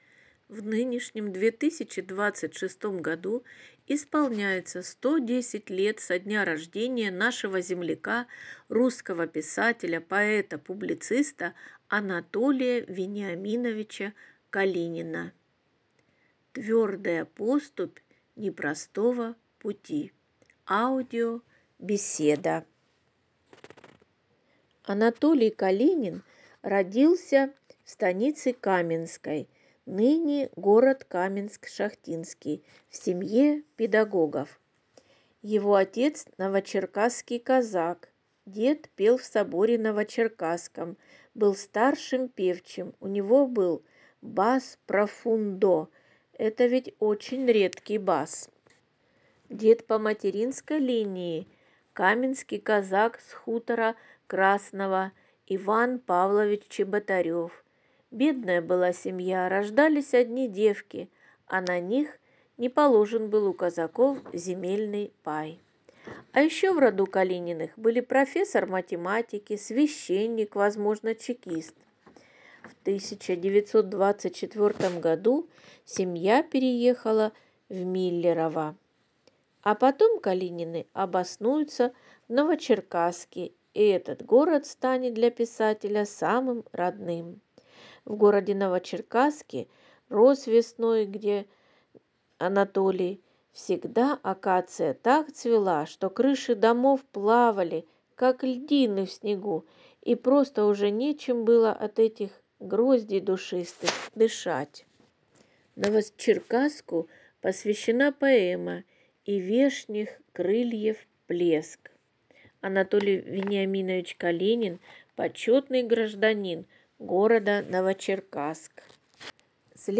Аудиобеседа «